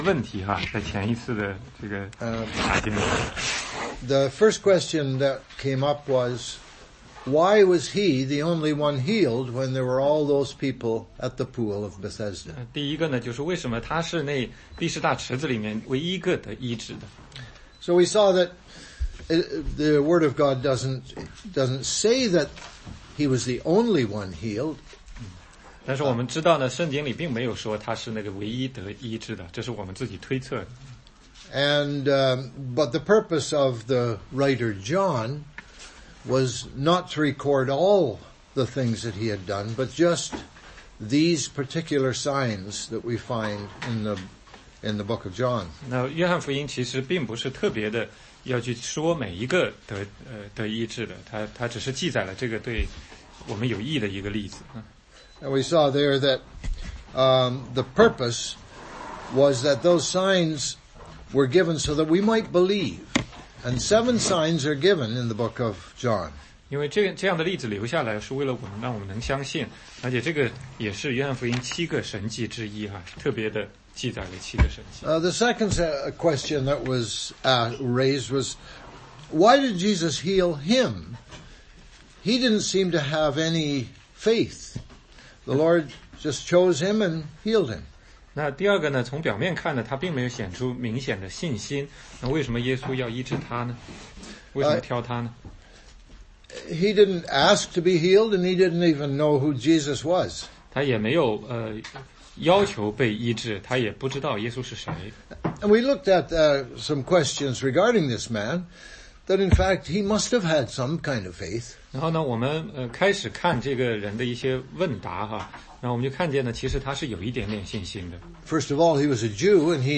16街讲道录音 - 约翰福音5章14-18